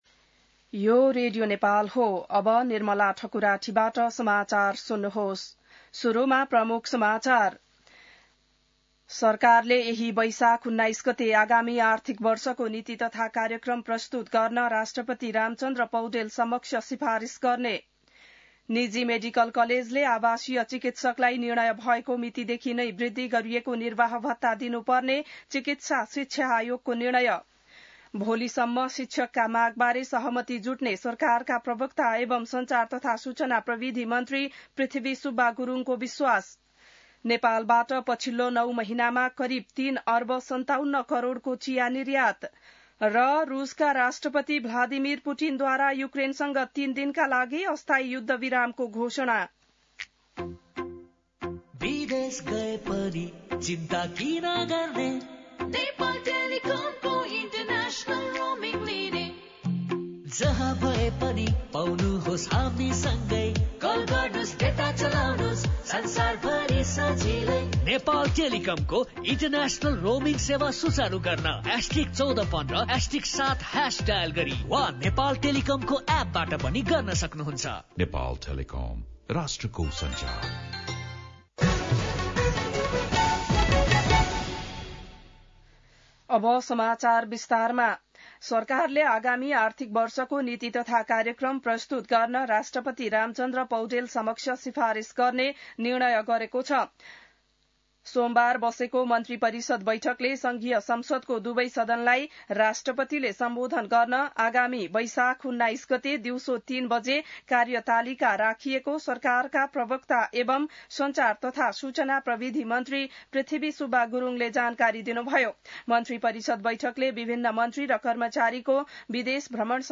बिहान ७ बजेको नेपाली समाचार : १६ वैशाख , २०८२